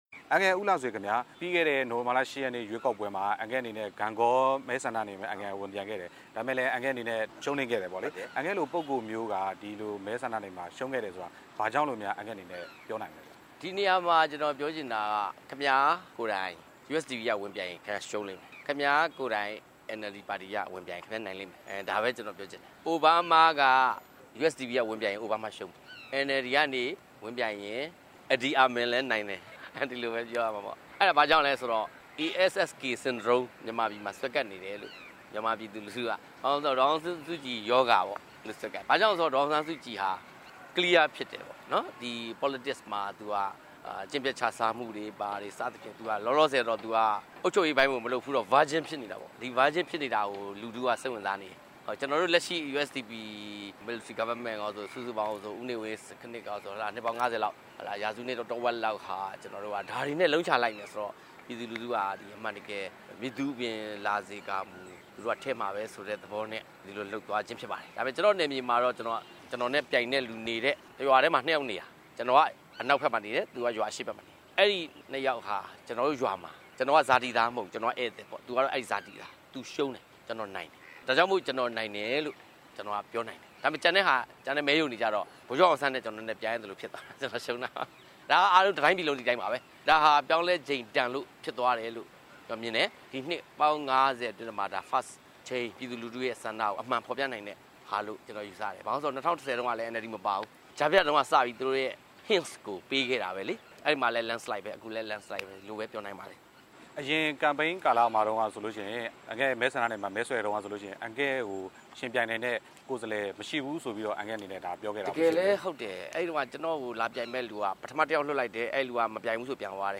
ဦးလှဆွေ နဲ့ မေးမြန်းချက်
ဒီကနေ့ RFA နဲ့ ရန်ကုန်မြို့မှာ တွေ့ဆုံမေးမြန်းရာမှာ ဦးလှဆွေက ကြံ့ခိုင်ဖွံဖြိုးရေးပါတီရဲ့ အားနည်းချက်ကြောင့် ရှုံးနိမ့်တာလို့ ပြောပါတယ်။